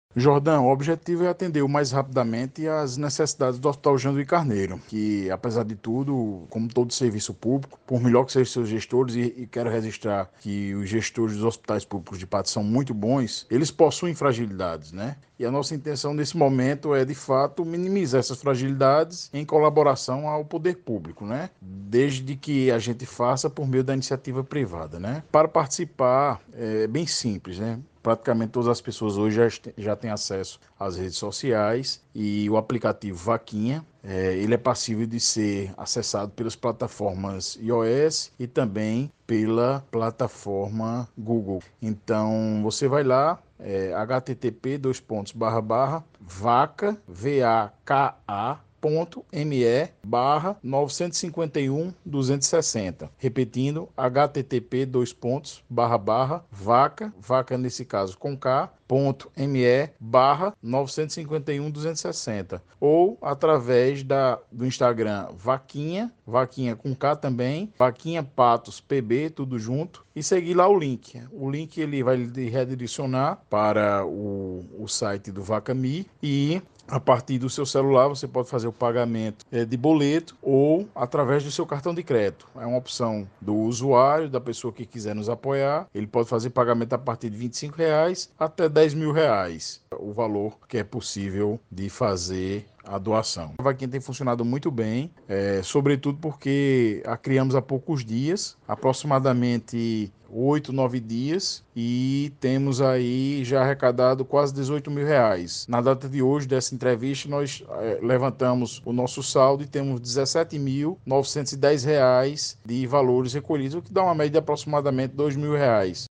Advogado fala sobre campanha para destinar recursos ao Hospital de Patos.